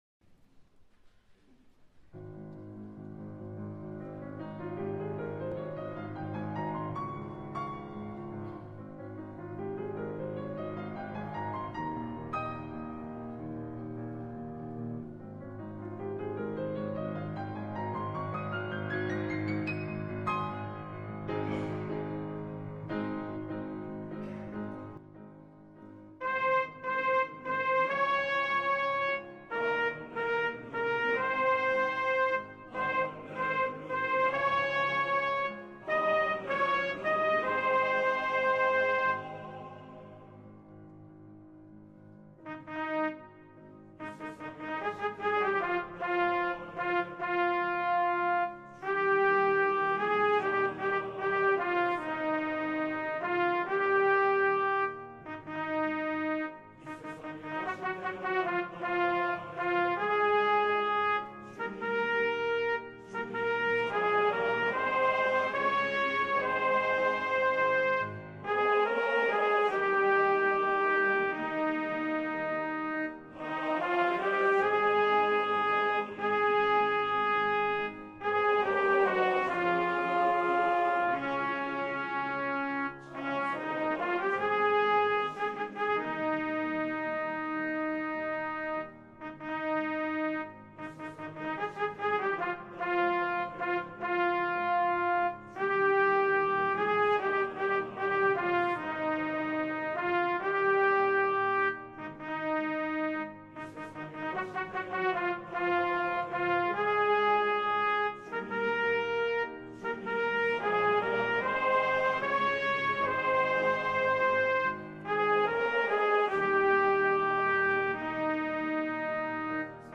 장로성가단 연습음원